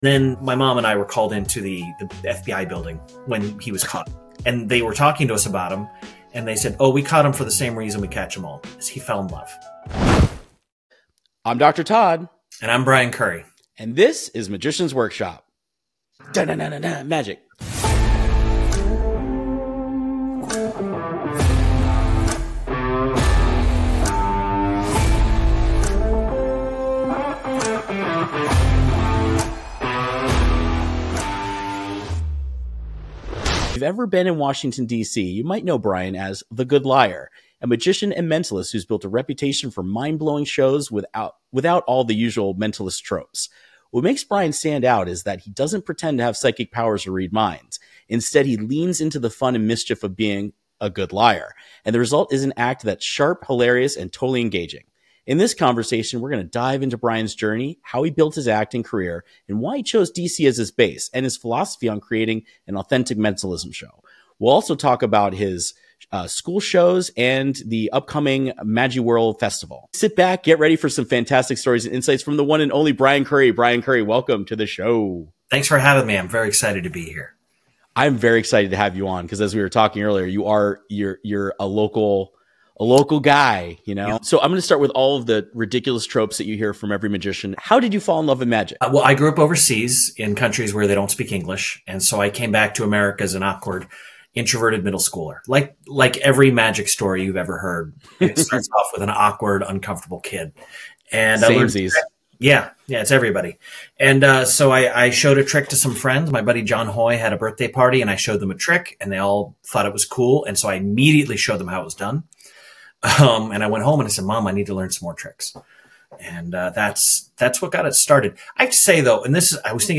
Confessions of the Good Liar. Interview